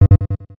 incorrect.m4a